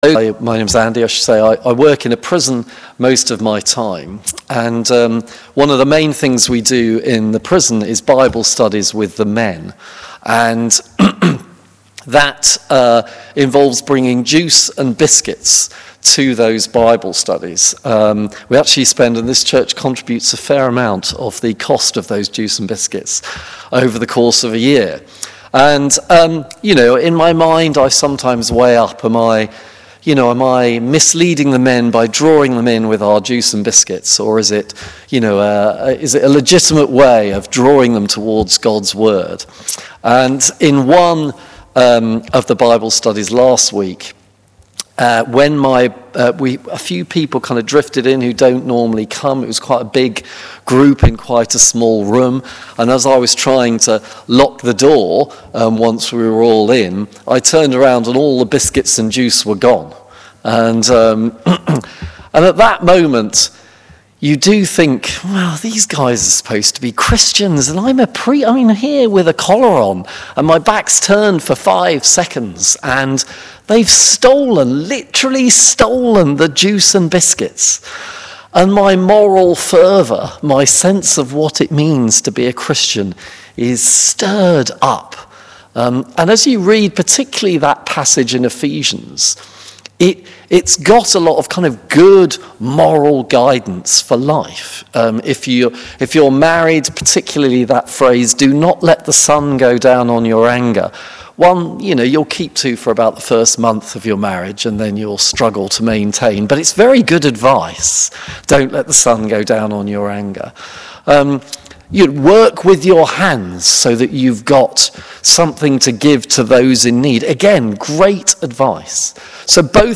Please listen to our 8am Sermon here: